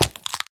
Minecraft Version Minecraft Version latest Latest Release | Latest Snapshot latest / assets / minecraft / sounds / mob / turtle / egg / jump_egg4.ogg Compare With Compare With Latest Release | Latest Snapshot
jump_egg4.ogg